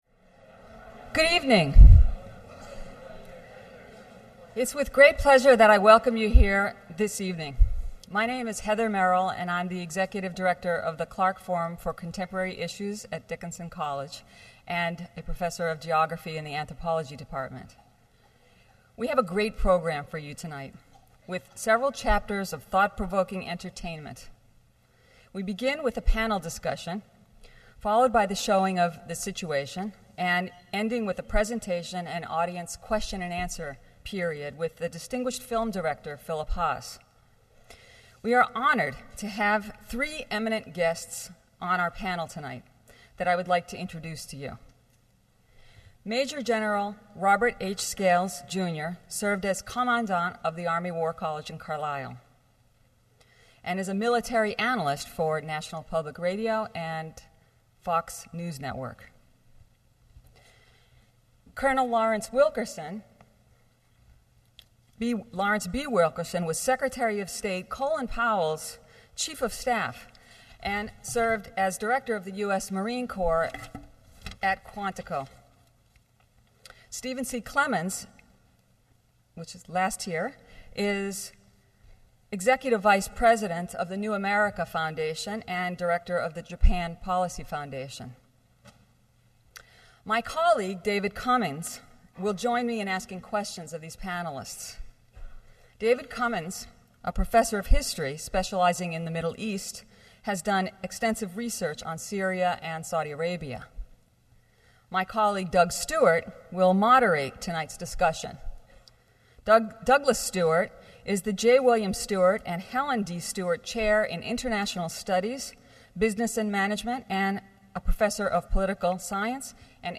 Saturday, March 24, 2007 Panel Discussion about Iraq, prior to the film showing of The Situation.